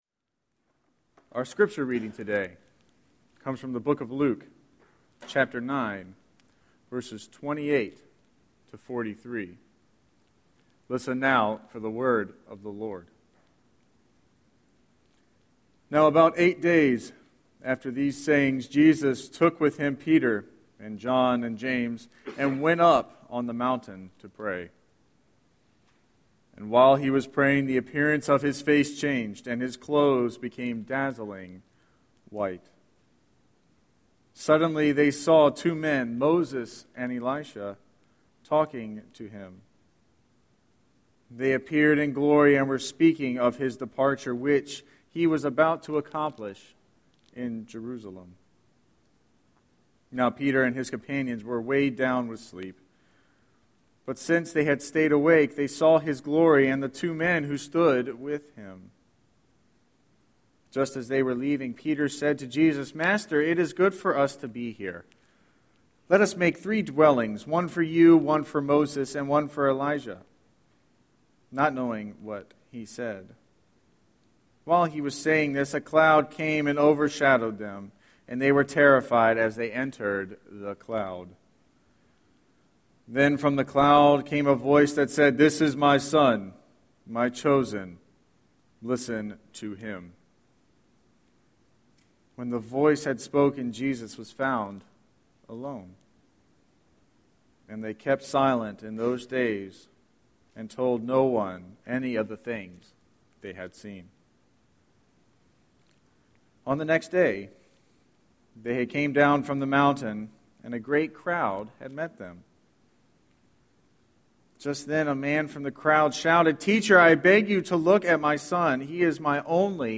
02-07-Scripture-and-Sermon.mp3